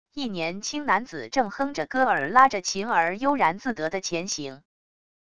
一年轻男子正哼着歌儿拉着琴儿悠然自得的前行wav音频